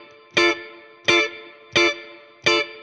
DD_StratChop_85-Dmin.wav